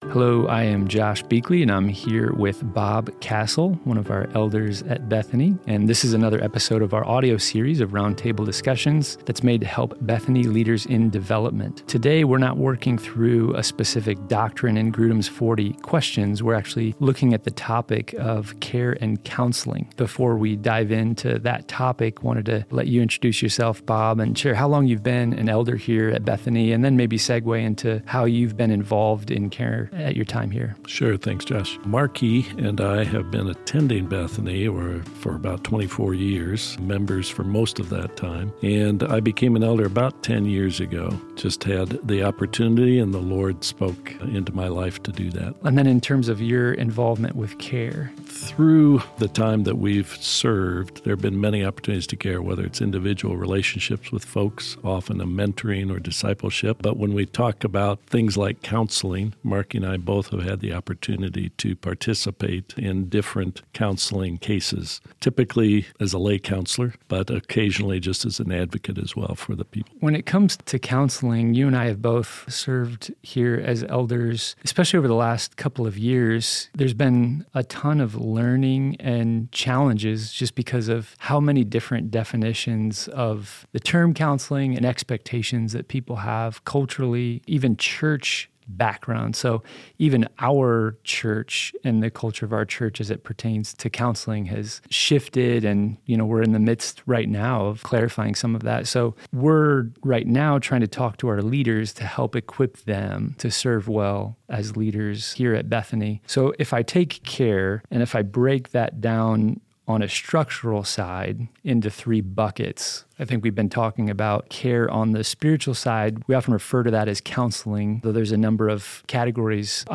This roundtable discussion